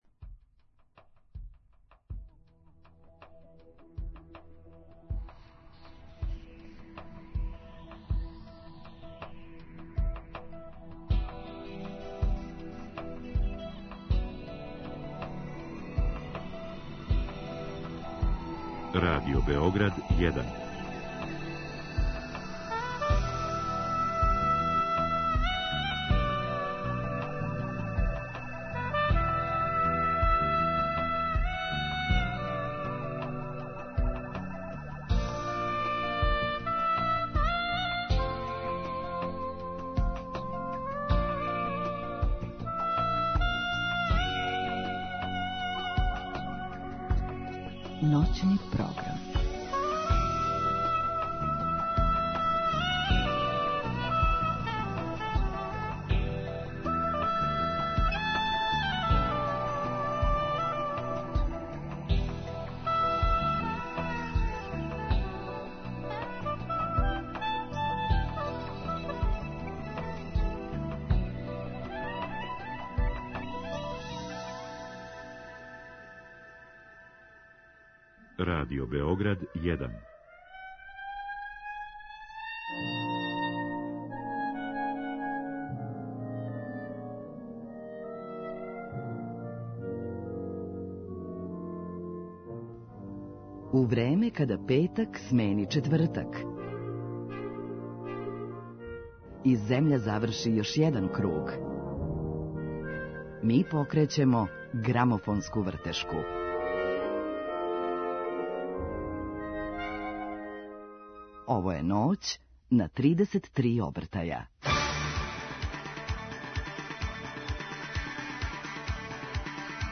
У Ноћном програму имаћемо прилике да чујемо композиције са албума које су различитог темпа и сензибилитета и сазнамо како су настајале.